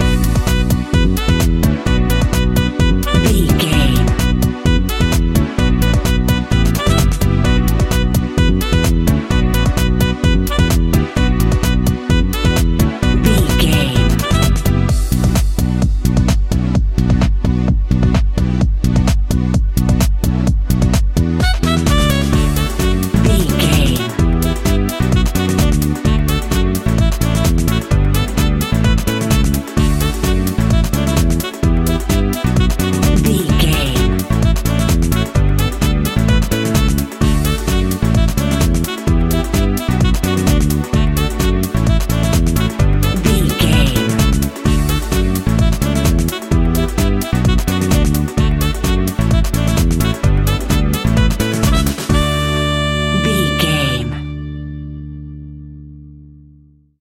Aeolian/Minor
groovy
uplifting
energetic
bass guitar
drums
strings
saxophone
piano
electric piano
disco
synth
upbeat